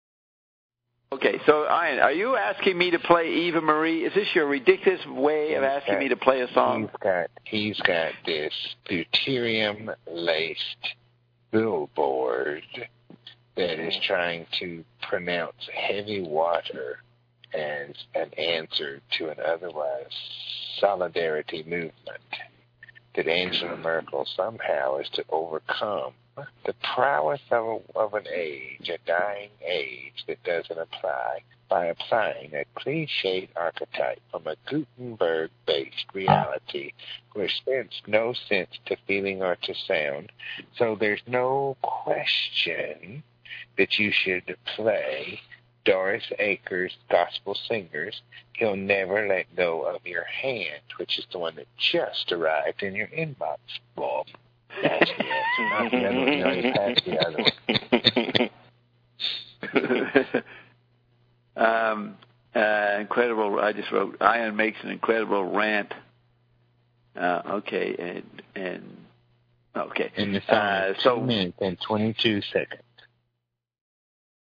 rant